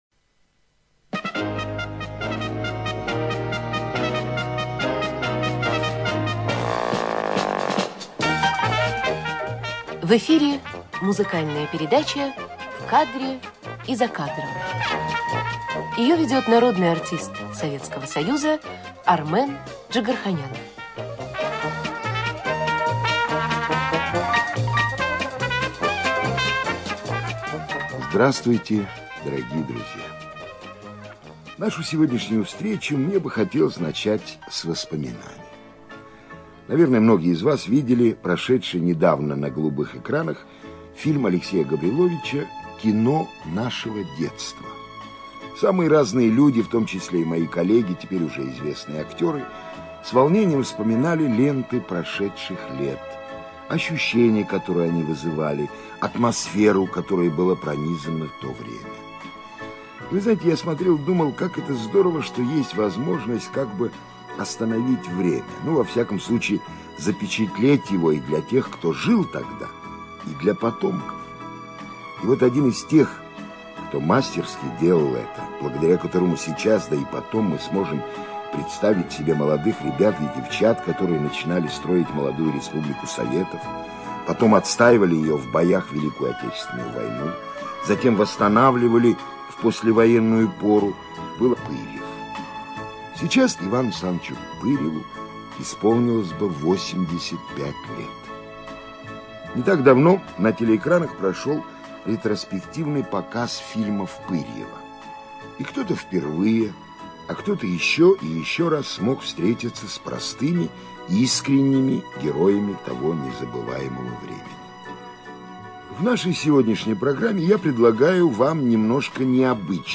ЧитаетДжигарханян А.